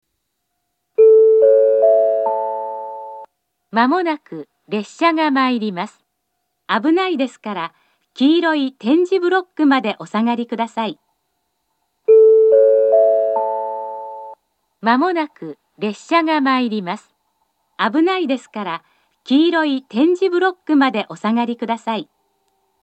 接近放送は何故か上下とも１番線のスピーカーから流れます。
２番線接近放送